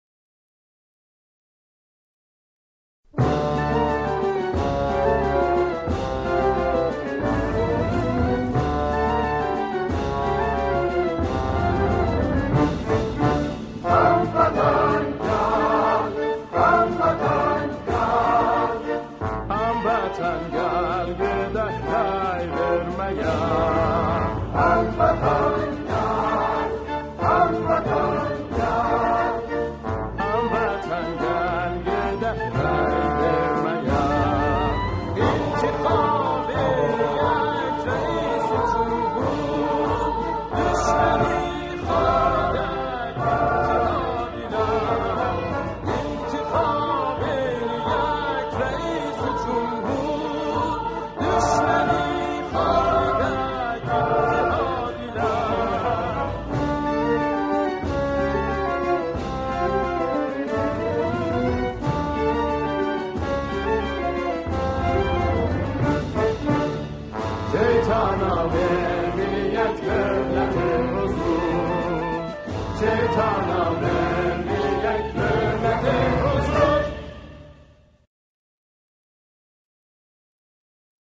همخوانی
گروهی از جمعخوانان
او این سرود را با گویش آذری سروده است.